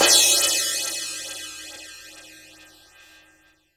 Crashes & Cymbals
pcp_crash03.wav